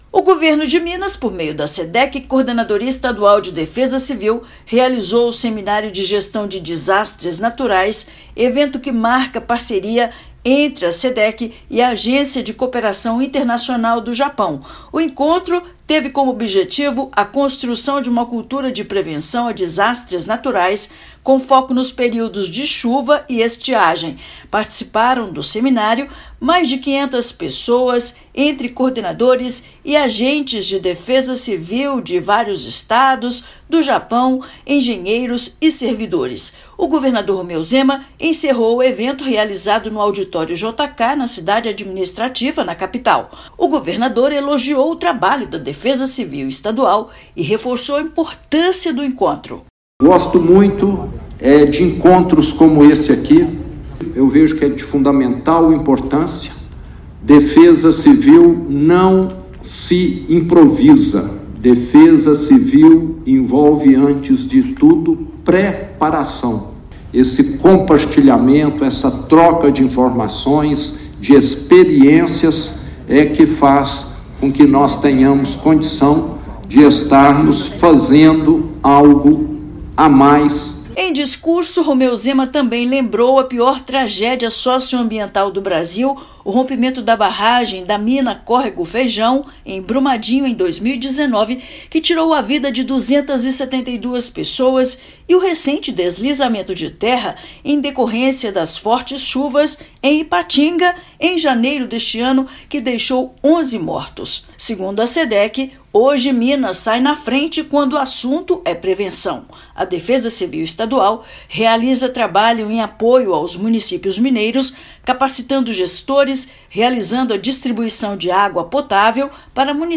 Parceria inédita busca intercâmbio de conhecimentos para aprimorar a gestão de riscos no estado. Ouça matéria de rádio.